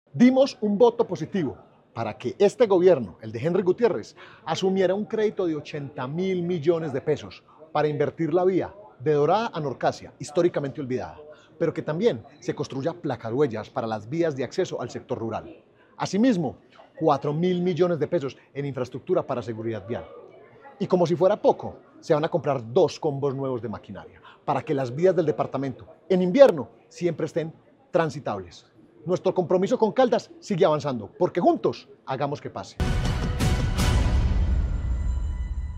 Diputado de Caldas, David Islem Ramírez.